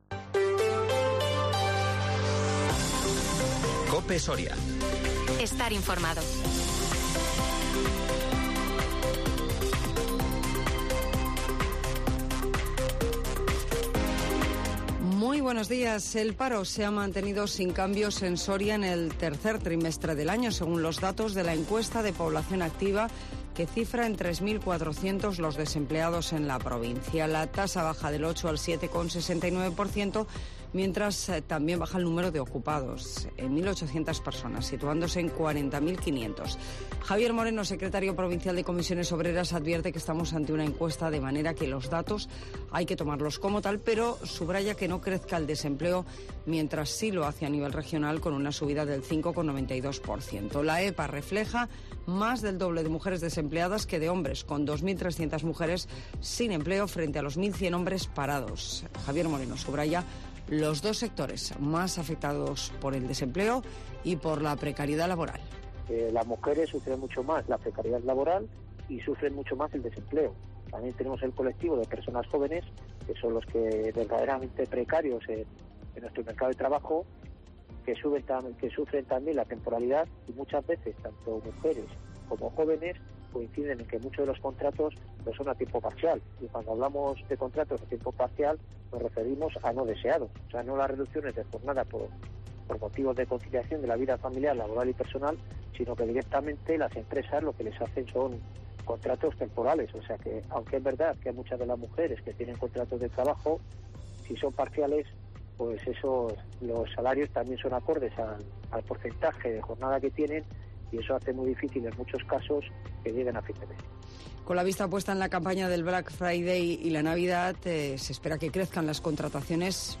AUDIO: Noticias locales en Herrera en COPE